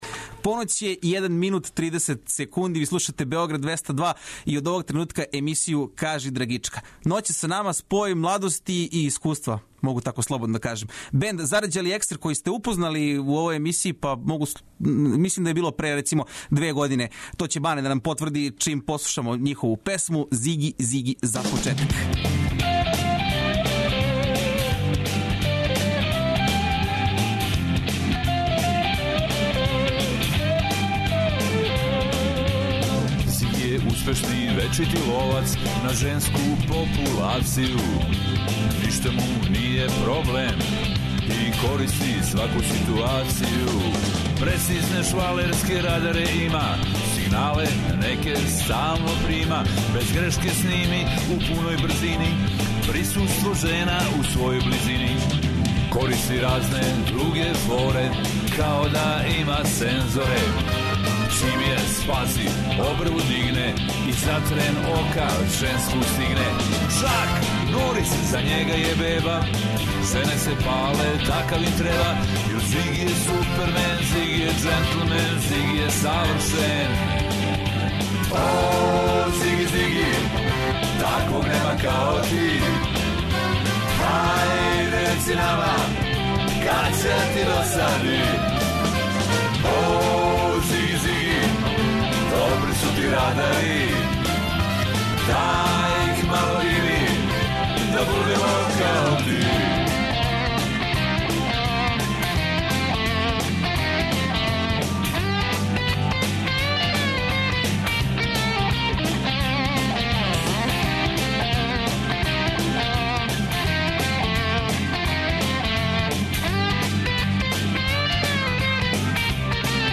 У вечерашњој Драгички представљамо вам бенд који сте већ упознали на таласима 202, групу ЗАРЂАЛИ ЕКСЕР. Спој младости и искуства и овог пута представиће нам нове снимке, и доносе нам гомилу лепих вести!